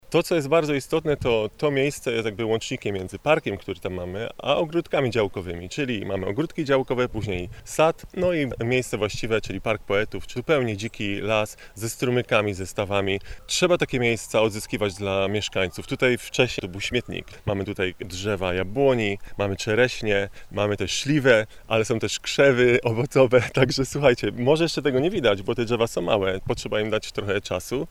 Radny miejski Filip Czeszyk podkreśla, że miasto powinno odzyskiwać takie miejsca i tworzyć dla mieszkańców miejsca rekreacji i wypoczynku: